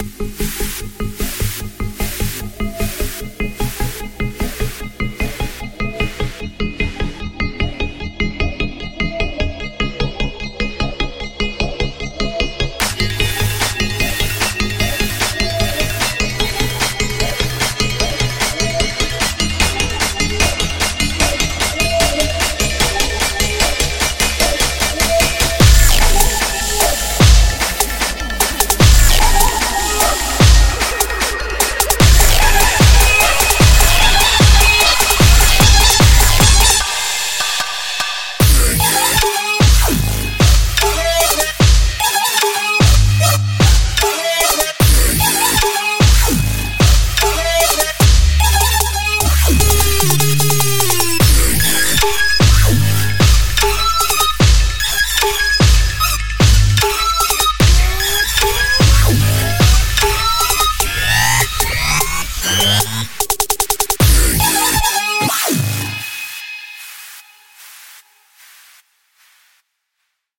【独家发行 | Hybrid Trap风格血清预置+皮肤包】Delicate Beats Invader
这 不是 您的普通预设合成银行…。声音在这个包是 不同 与 GROOVY
• 低音合成器
• 和弦合成器
• FX声音
• 鼓合成器声音
• 说话的合成器声音